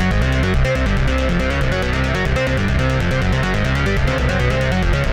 Index of /musicradar/dystopian-drone-samples/Droney Arps/140bpm
DD_DroneyArp2_140-C.wav